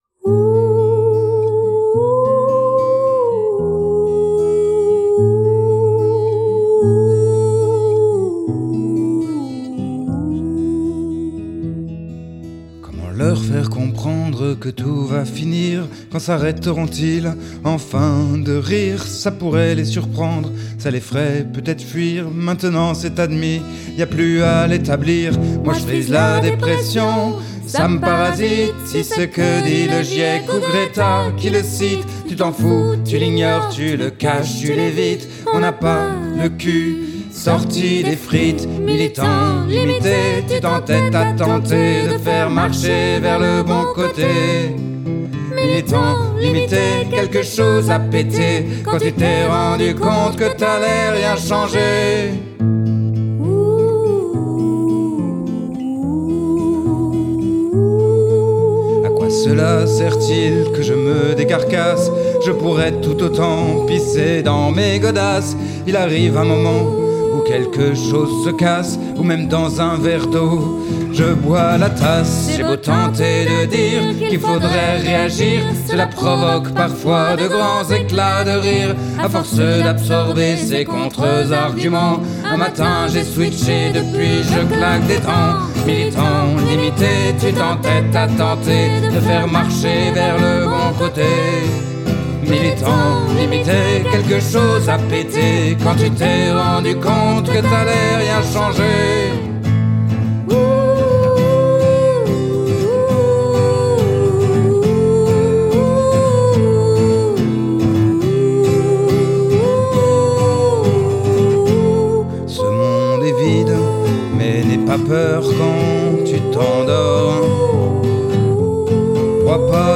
Style musical : guitare et chant